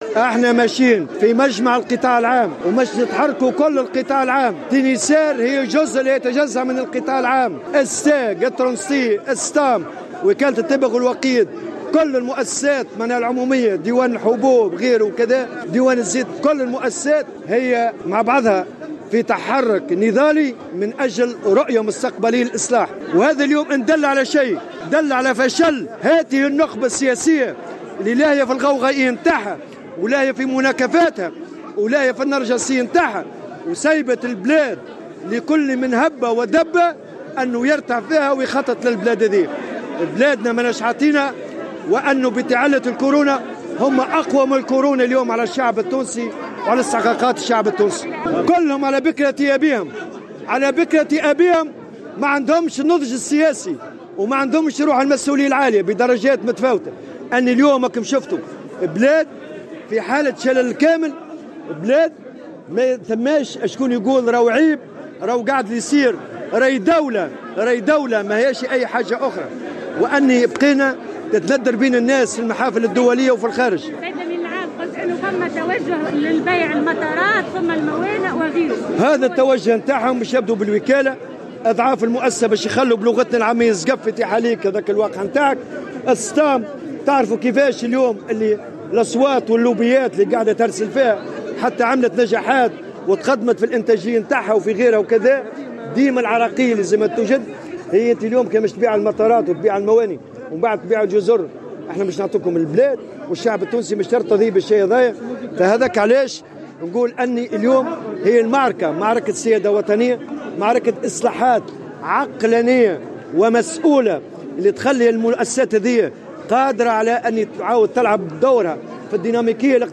وبين الطبوبي خلال تجمع عمالي بمقر شركة الخطوط التونسية، أن هناك محاولة و مخططا ممنهجا لتدمير القطاع العام من قبل الحكومات المتعاقبة، مشيرا الى انفتاح المنظمة على الإصلاح ولكن ليس بمفهموم من وصفهم "بالسمسارة"، منتقدا لقاءات الرئيسة المديرة العامة لشركة الخطوط التونسية ألفة الحامدي، مع عدد من السفراء.